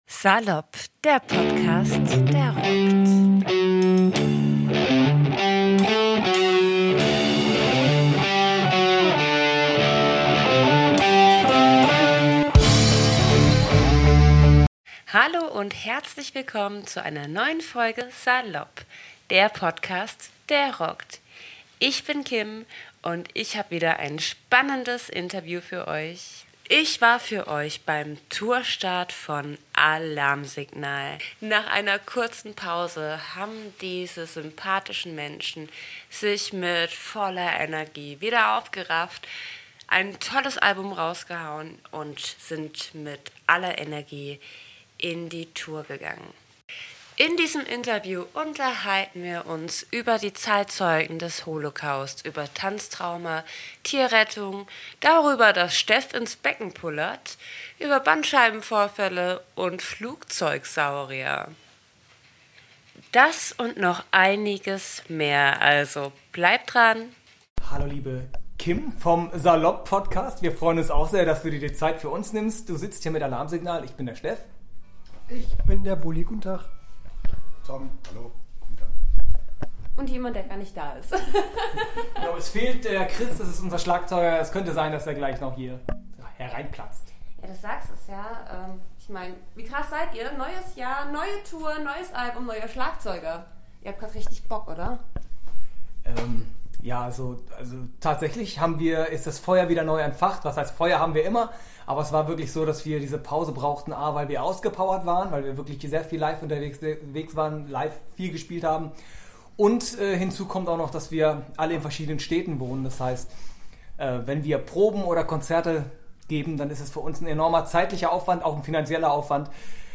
Ich durfte Alarmsignal zum ausverkauften Tourstart in Wiesbanden begleiten. Nach einer herzlichen Begrüßung wurde ich auch schon in den Backstagebereich geführt, indem ich die Bandmitglieder von Alarmsignal ordentlich unter die Lupe ngenommen habe.
Alarmsignal im Interview – Punk ist ständig in Bewegung